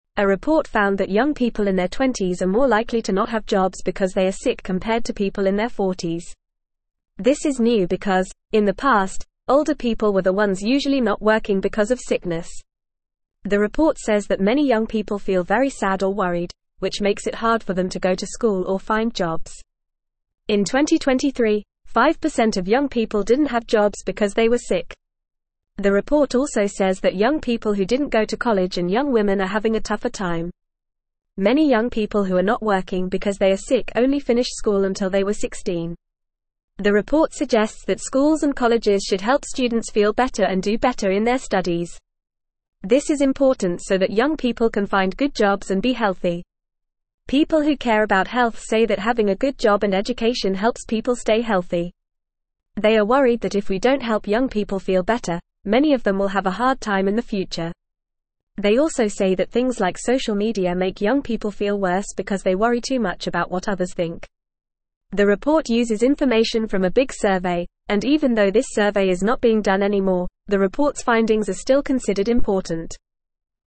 Fast
English-Newsroom-Lower-Intermediate-FAST-Reading-Young-People-Feeling-Sad-and-Not-Working.mp3